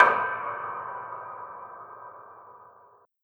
BWB WAV R US PERC (3).wav